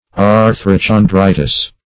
Search Result for " arthrochondritis" : The Collaborative International Dictionary of English v.0.48: Arthrochondritis \Ar`thro*chon*dri"tis\, n. [NL.]